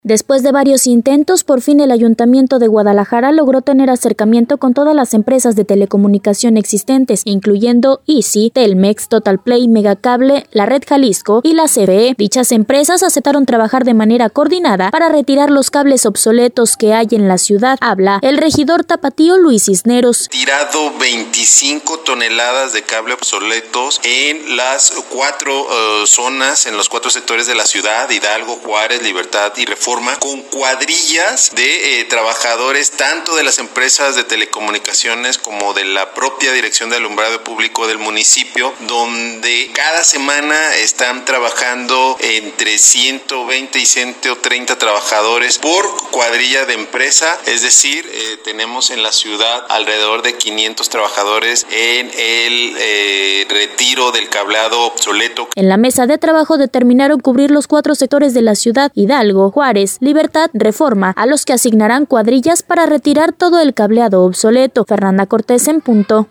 Después de varios intentos, por fin el Ayuntamiento de Guadalajara, logró tener acercamiento con todas las empresas de telecomunicación existentes, incluyendo a Izzi, Telmex, Totalplay, Megacable, la Red Jalisco y la Comisión Federal de Electricidad, dichas empresas aceptaron trabajar de manera coordinada para retirar los cables obsoletos, que hay en la ciudad, habla el Regidor Tapatío Luis Cisneros.